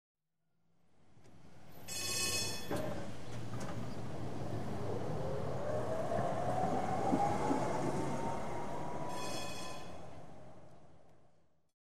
Звук удаляющегося трамвая с гудком